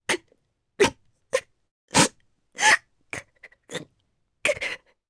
Naila-Vox_Sad_jp.wav